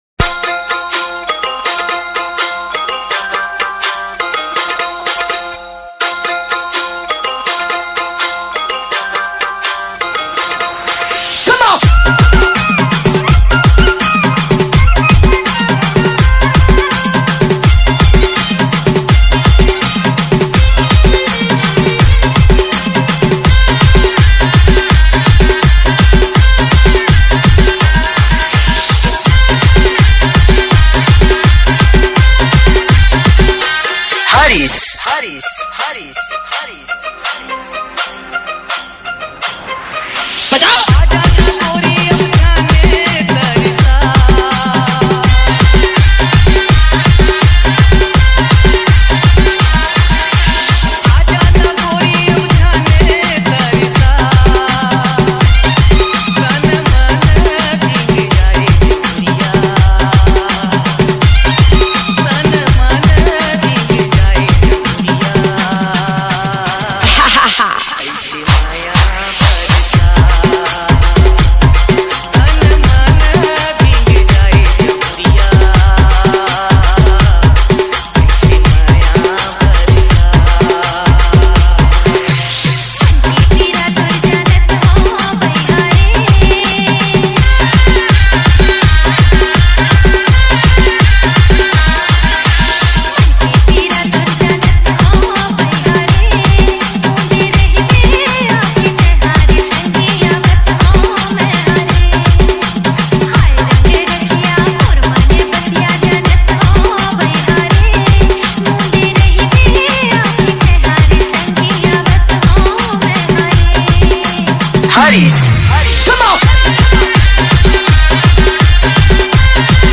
CG LOVE DJ REMIX